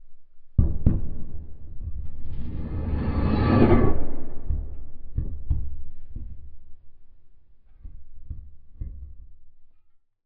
cave10.ogg